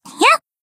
BA_V_Hanako_Swimsuit_Battle_Shout_1.ogg